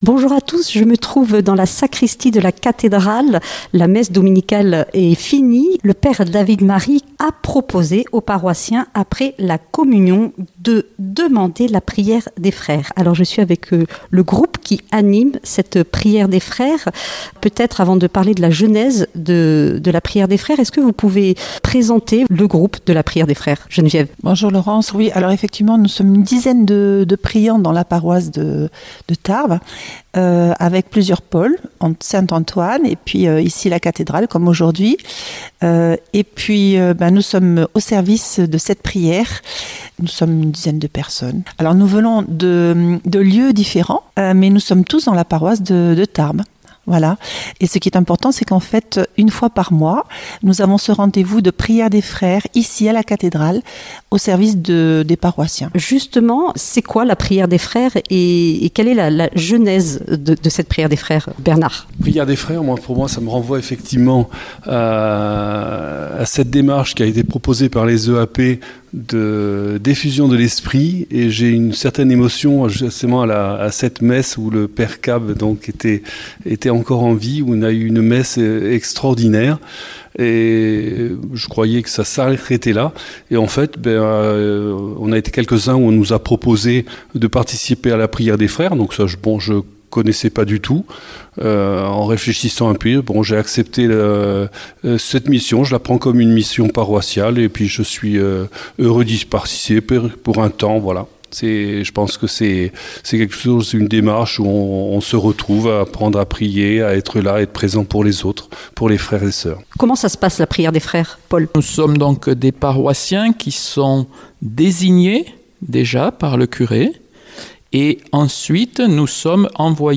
Présentatrice